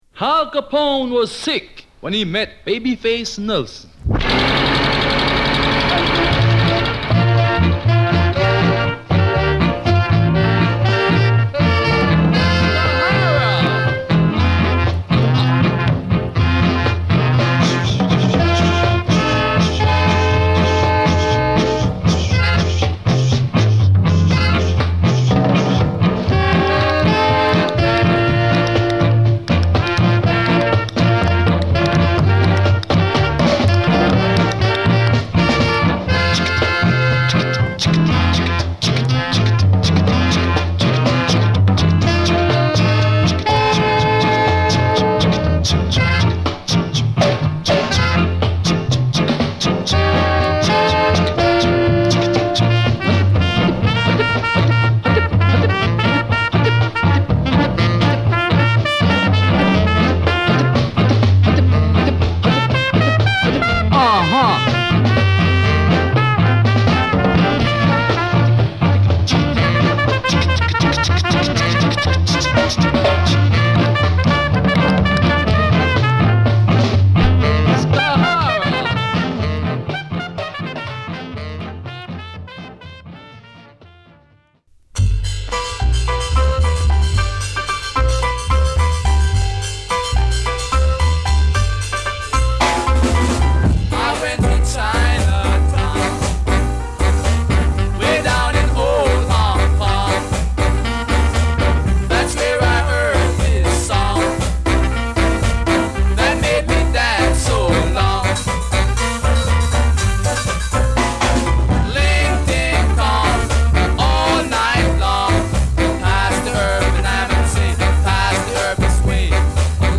勢いのあるミッドテンポのリズムに口チュク全開のキラー・スカ！